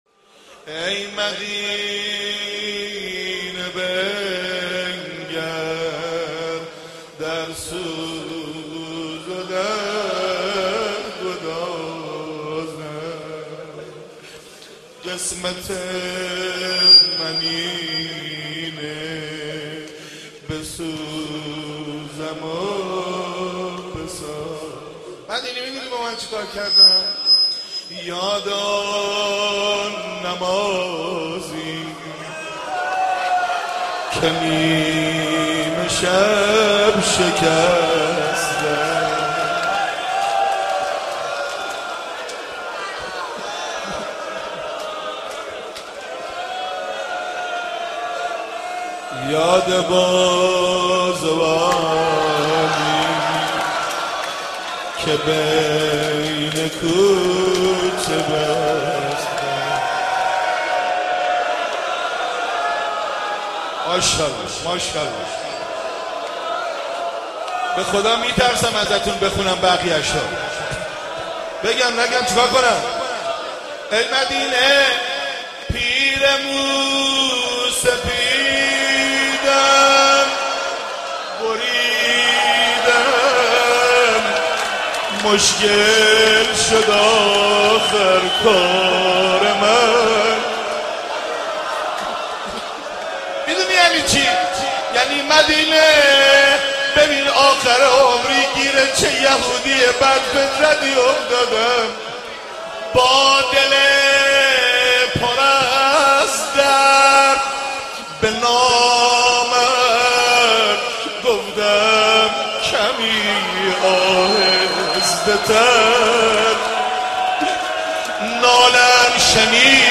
روضه شهادت امام صادق